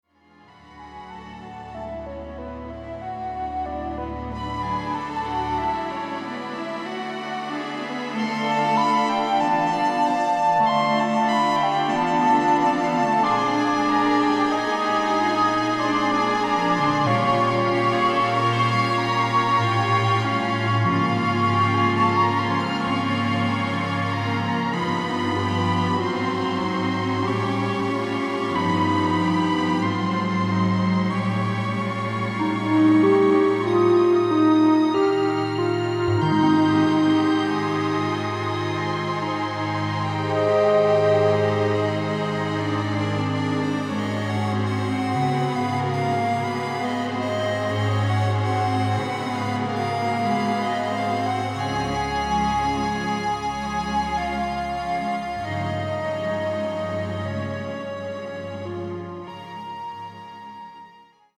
SONAR Home Studio was used during mix down for reverb and delay effects.
ELECTROLUMINESCENCE is the first CD I have offered featuring my MFOS modular synthesizer and the ULTIMATE semi-modular synthesizer.
Completely voiced with the MFOS ULTIMATE.